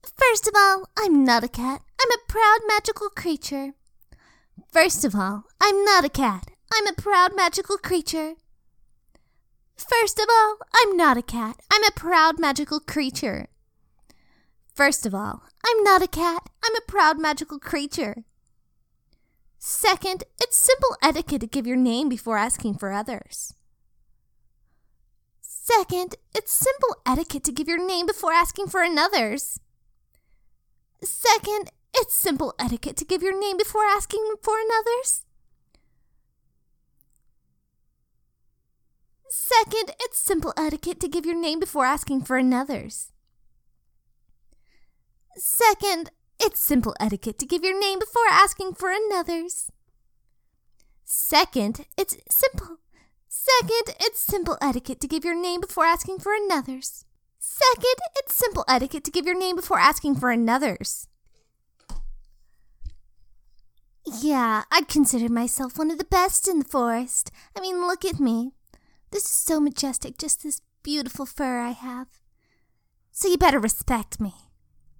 Voice Actor
Voice: Slightly proud, high to mid-deep pitch, possibly cute.